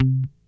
C3 4 F.BASS.wav